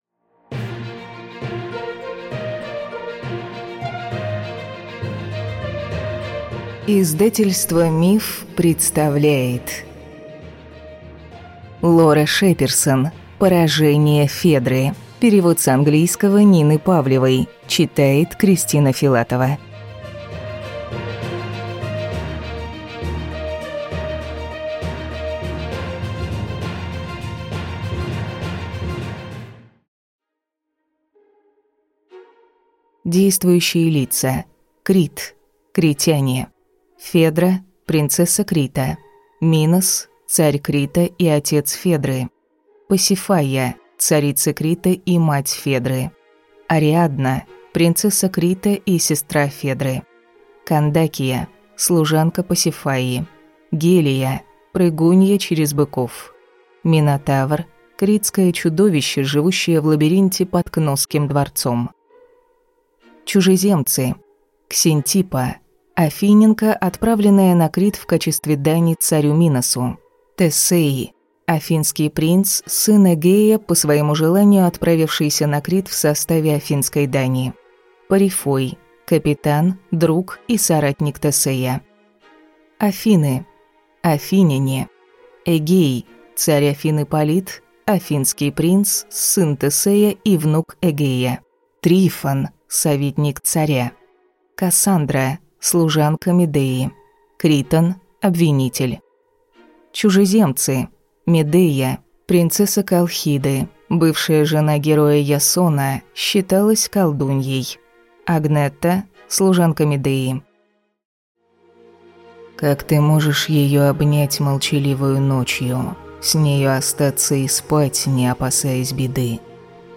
Аудиокнига Поражение Федры | Библиотека аудиокниг